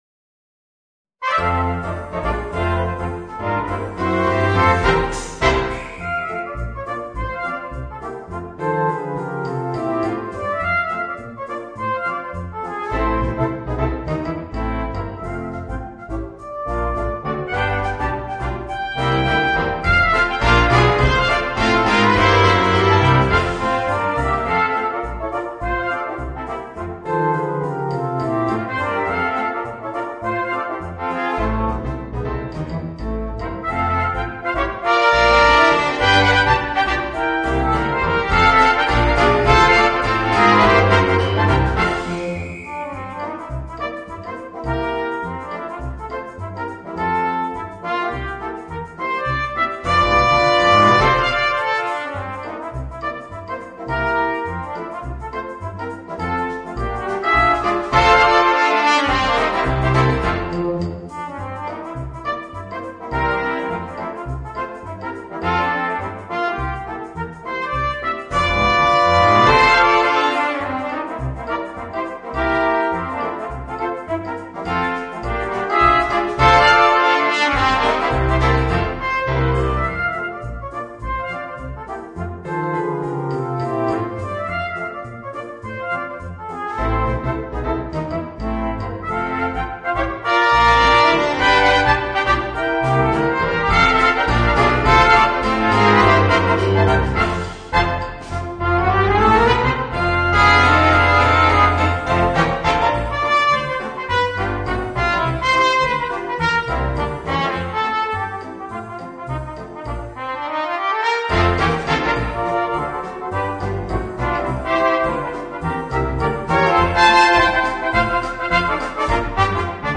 Voicing: 5 - Part Ensemble